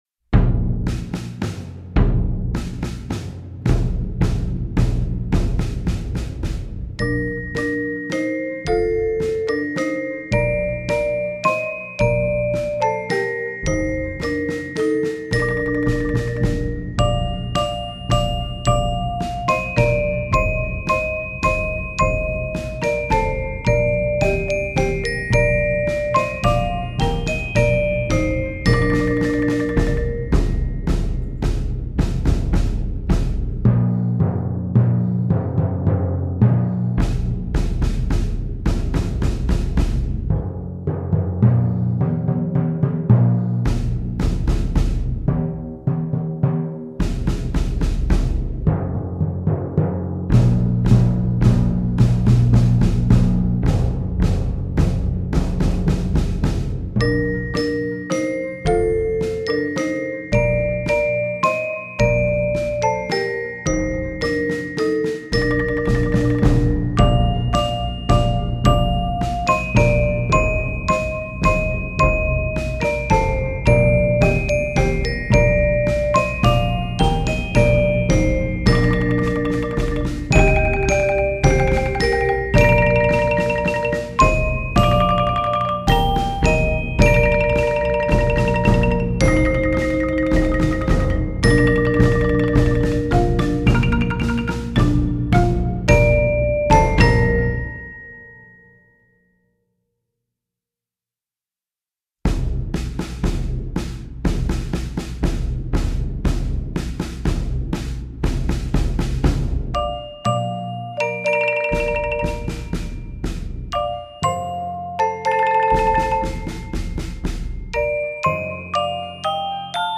Voicing: 5-7 Percussion